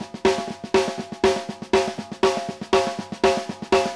TRAIN BEAT-L.wav